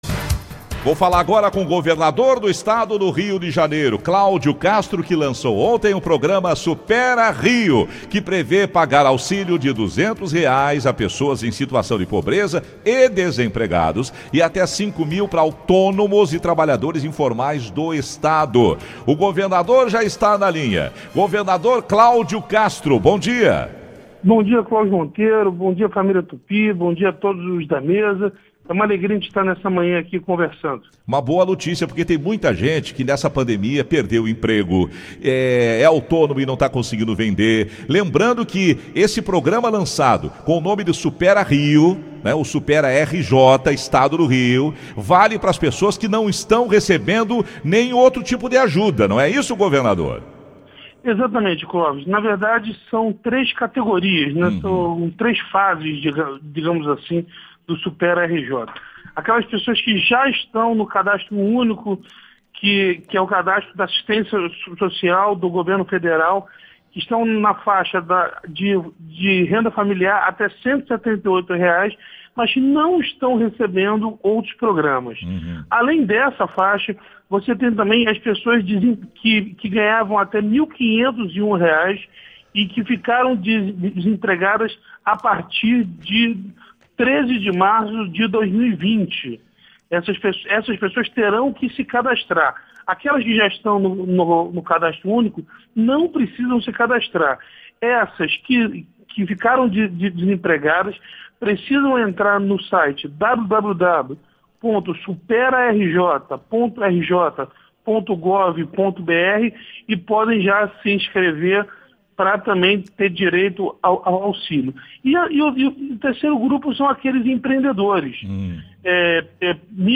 Ouça a entrevista completa de Cláudio Castro